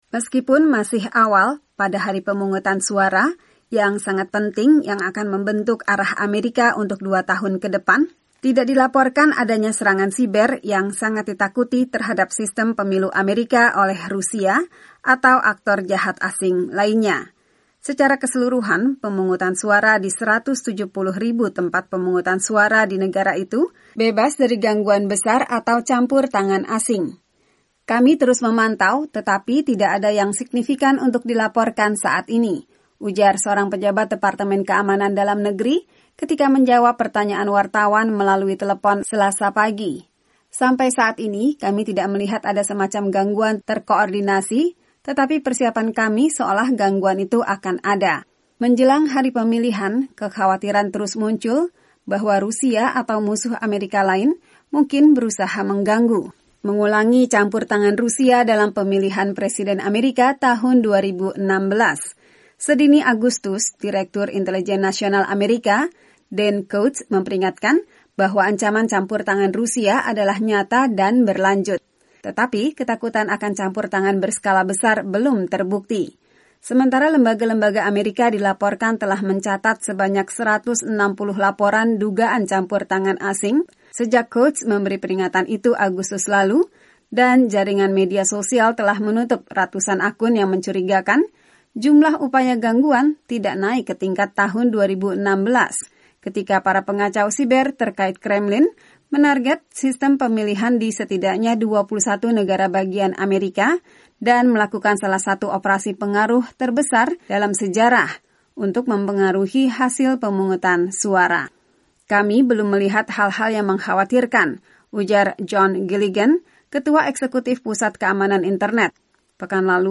Meskipun ada kekhawatiran mengenai campur tangan asing, pemungutan suara dalam pemilu paruh waktu di AS tahun 2018 dimulai relatif lancar hari Selasa dan pejabat- pejabat melaporkan tidak ada upaya gangguan. Laporan wartawan VOA